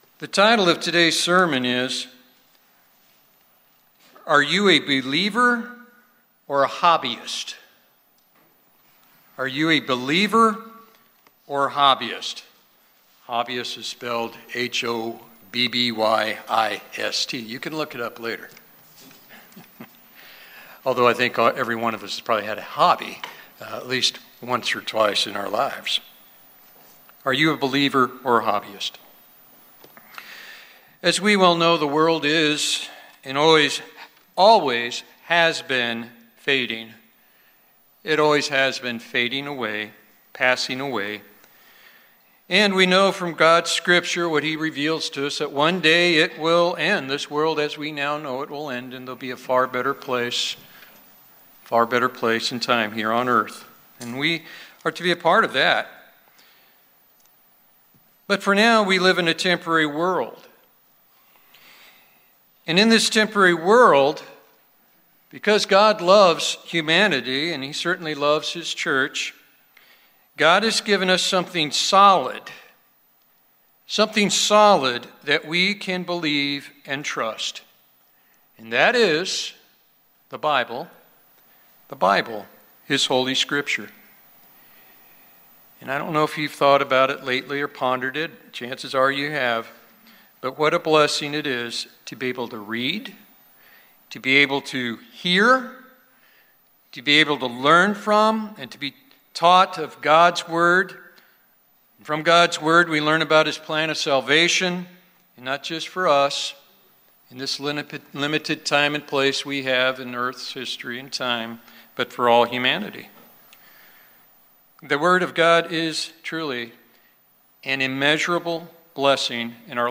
The title of today's sermon is, Are You a Believer or a Hobbyist?